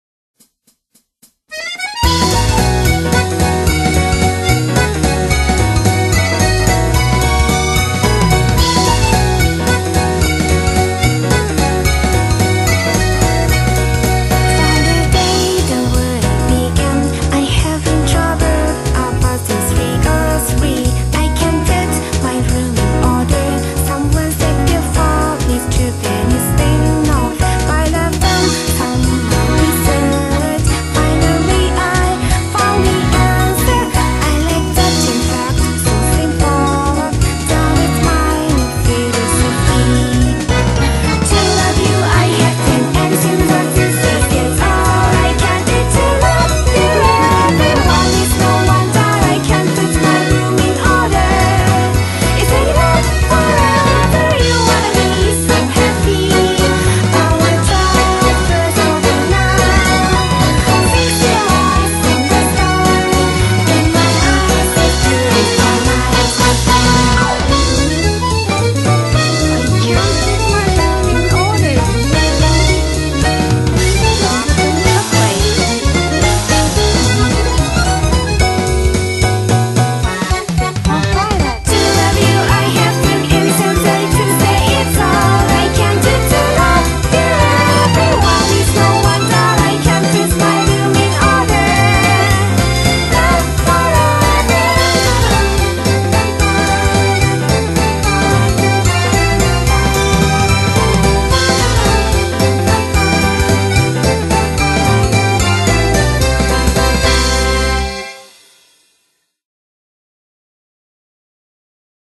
BPM220
Audio QualityPerfect (High Quality)
There are files in 3/4 time.